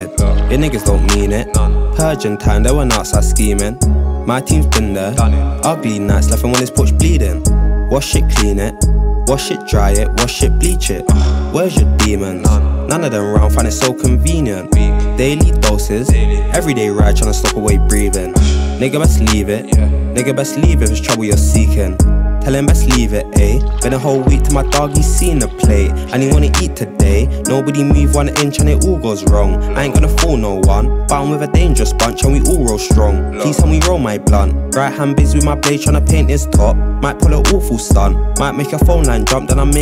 Kategorie Rap